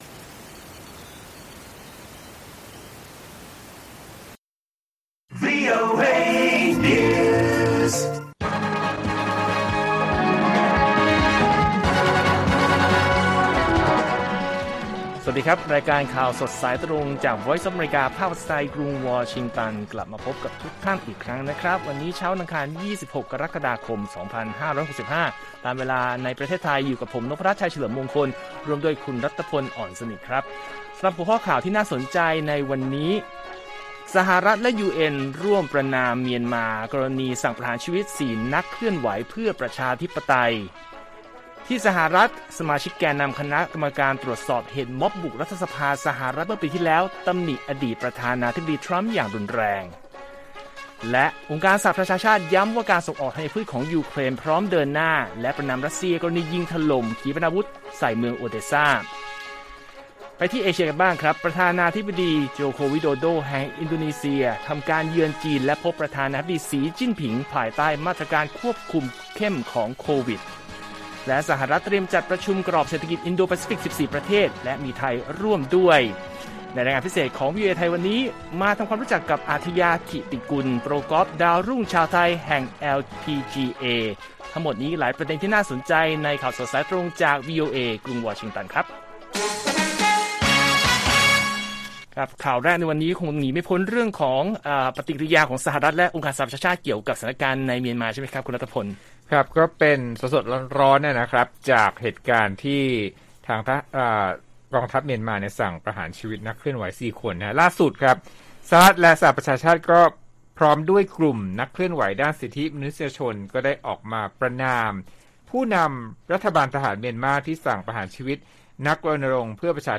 ข่าวสดสายตรงจากวีโอเอไทย 6:30 – 7:00 น. วันที่ 26 ก.ค. 65